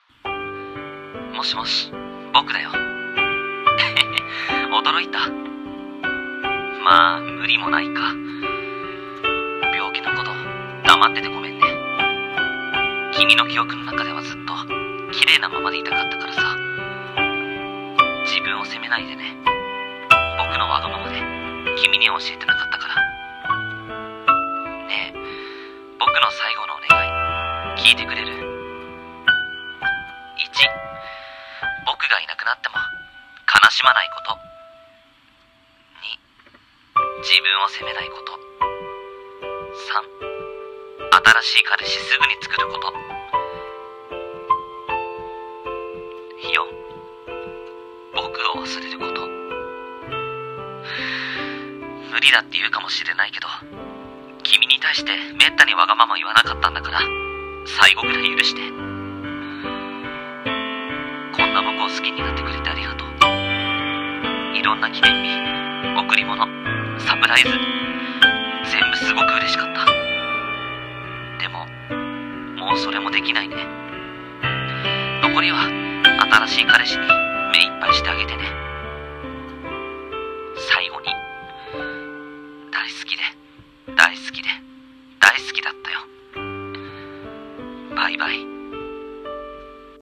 【一人声劇】最後の願い